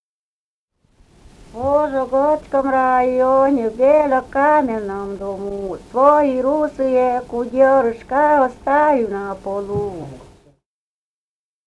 «В Вожёгодской районе» (рекрутская частушка).